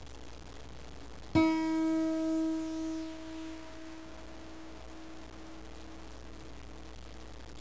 Tuning the Guitar
6. And the first string, which is on the bottom, sounds like
string1.wav